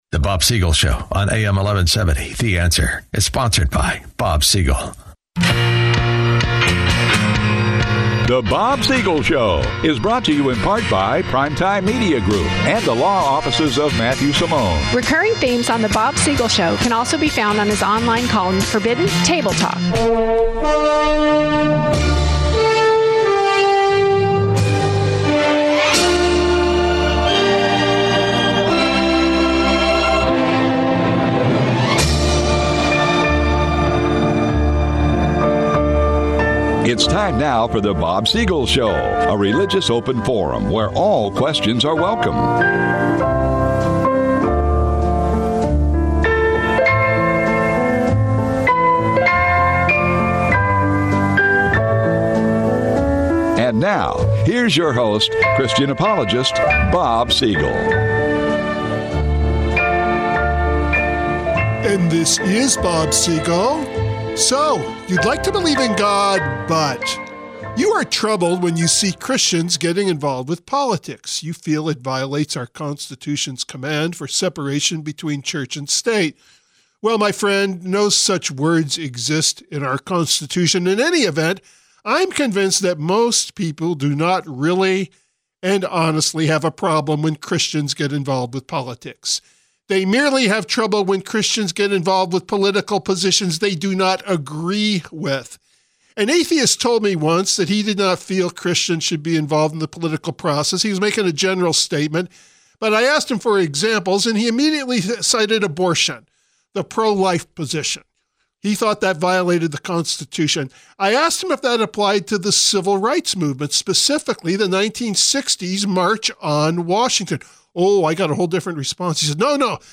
Interview: Alveda King
She talks about her commitment to Christ, her racial reconciliation and Pro-Life ministries, and her involvement with her uncle during the 1960’s civil rights movement. This interview lasts about 15 minutes and is played at the top of the program without commercial interruption.